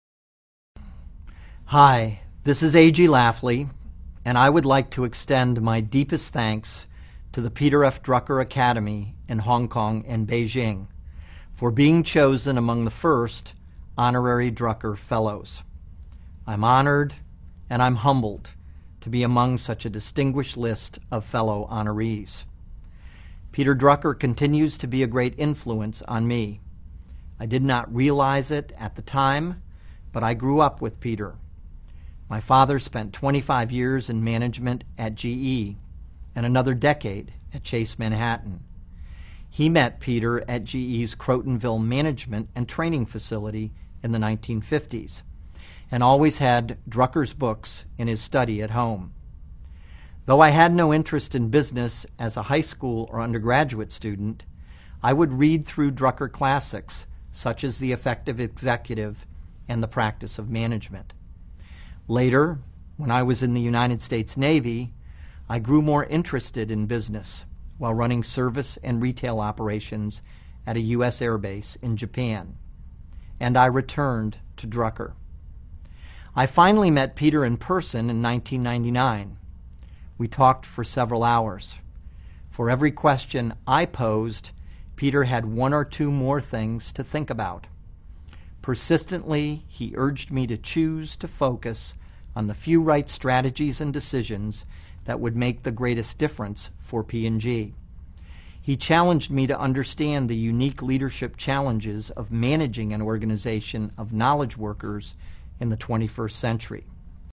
财富精英励志演讲60：以人为本的重要性(1) 听力文件下载—在线英语听力室
这些财富精英大多是世界著名公司的CEO，在经济领域成就斐然。在演讲中他们或讲述其奋斗历程，分享其成功的经验，教人执着于梦想和追求；或阐释他们对于公司及行业前景的独到见解，给人以启迪和思考。